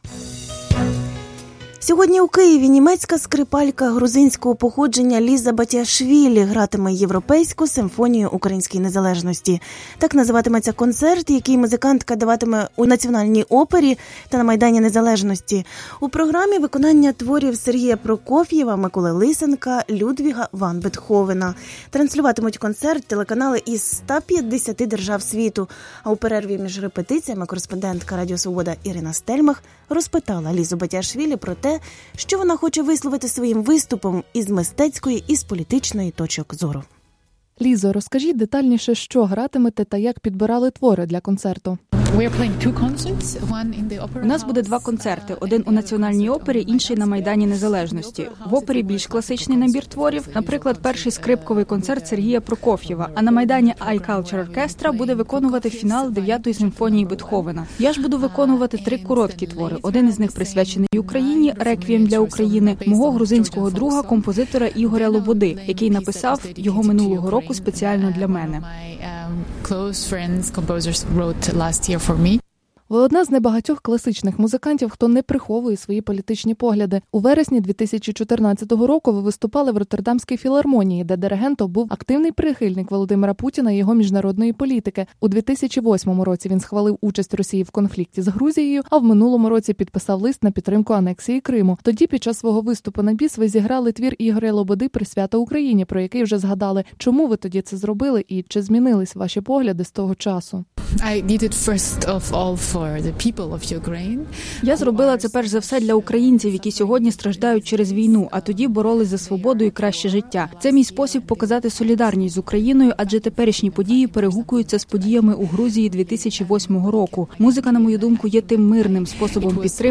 Скрипалька Ліза Батіашвілі
У перерві між репетиціями до концерту Радіо Свобода розпитало Лізу Батіашвілі про політичні погляди, які вона не тримає в таємниці та про музику, яку даруватиме українцям.